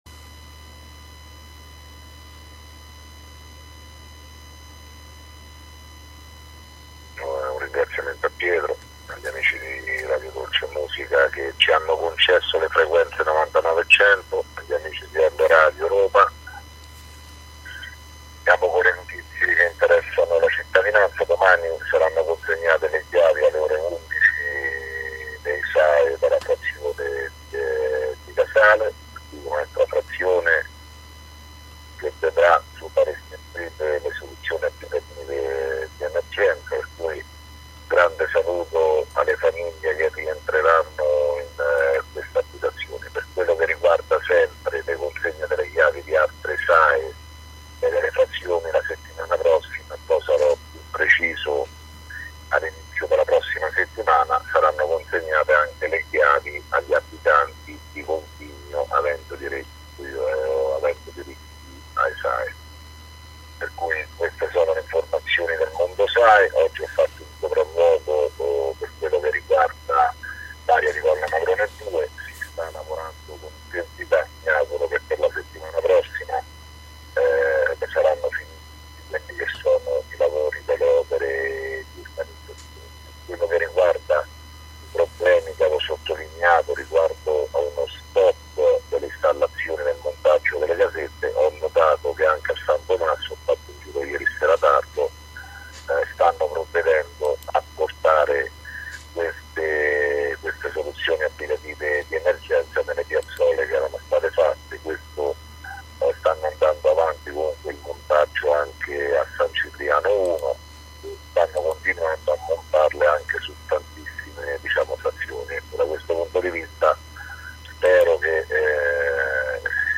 Di seguito il messaggio audio del Sindaco Sergio Pirozzi del 6 OTTOBRE 2017